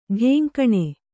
♪ ḍhēŋkaṇe